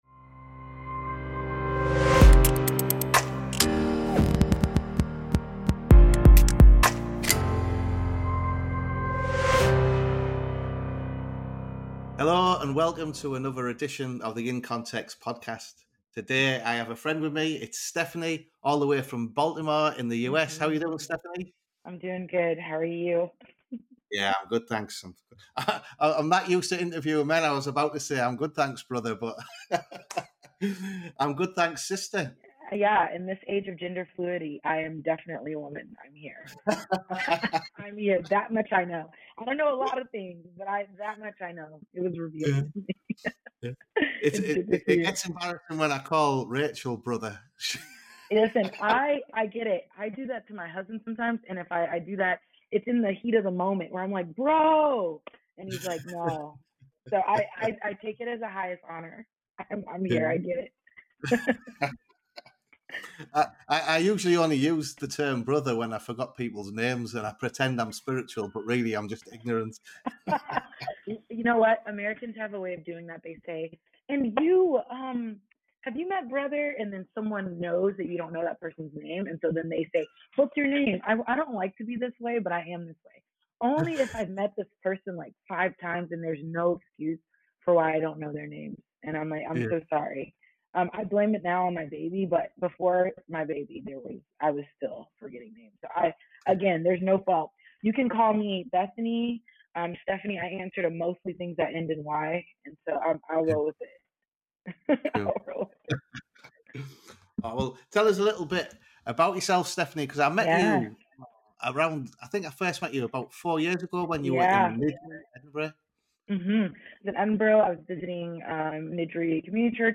In Context / Interview